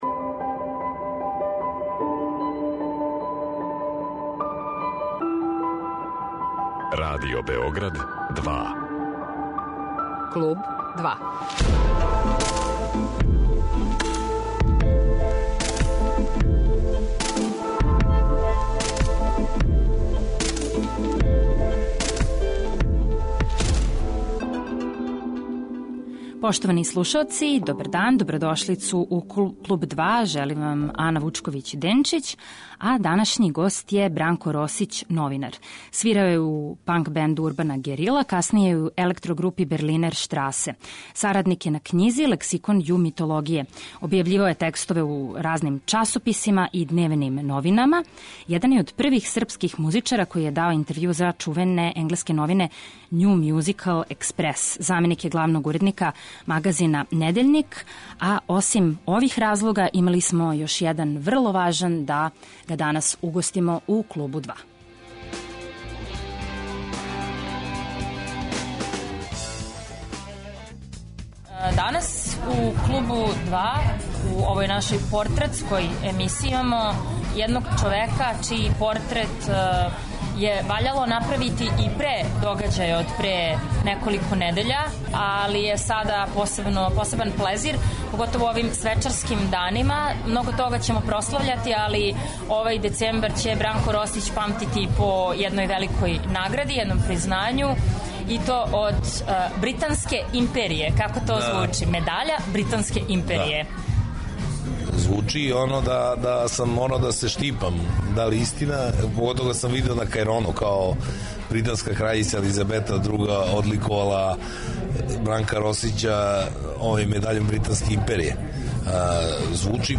Гост 'Клуба 2' је